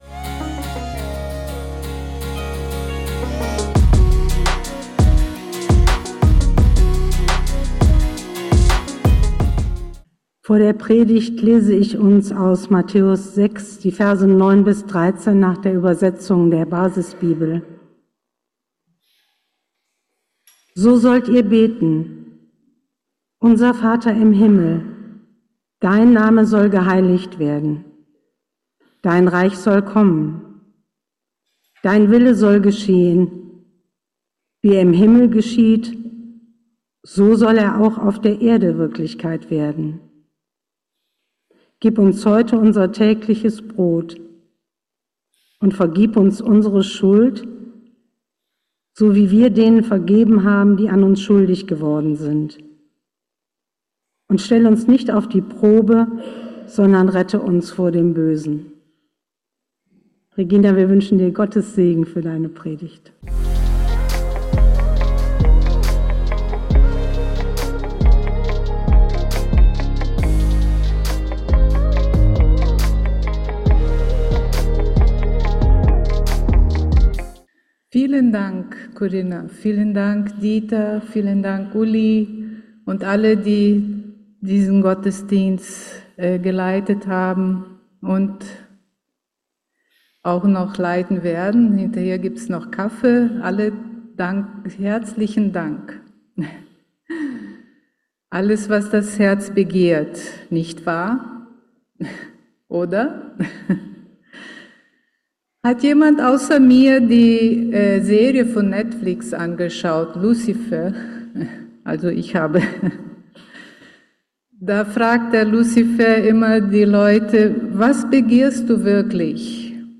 Geistliche Inputs, Andachten, Predigten